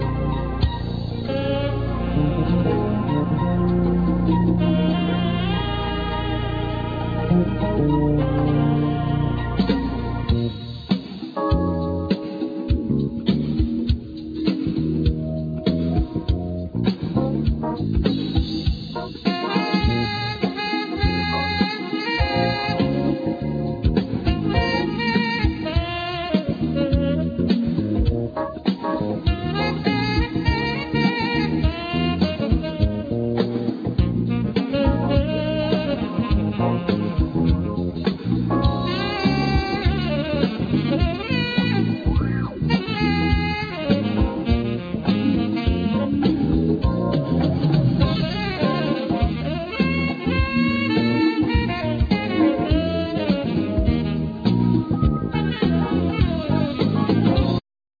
Alto sax,Synthesizer
Keyboards
Guitar
Bass
Tenor sax
Drums
Percussions